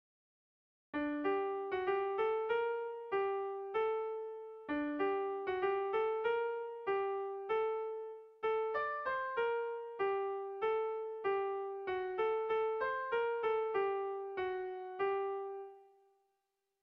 Kopla handiaren moldekoa
AABD